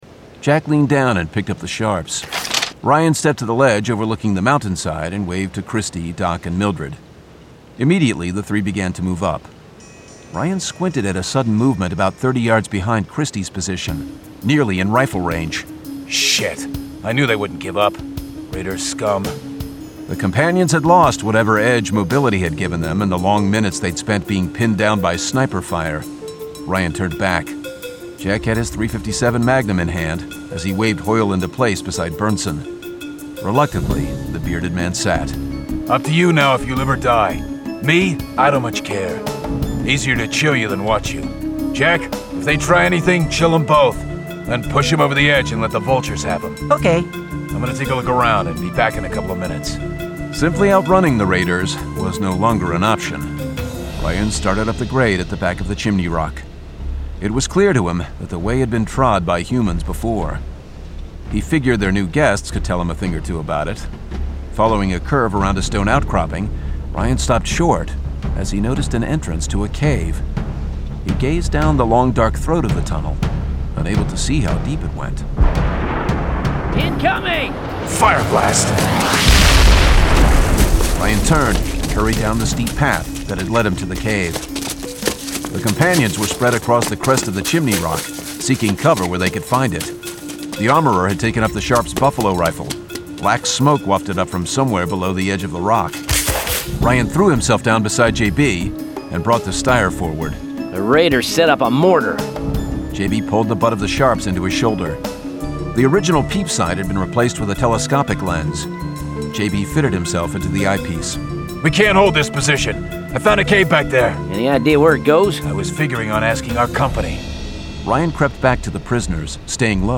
Deathlands 38: The Mars Arena [Dramatized Adaptation]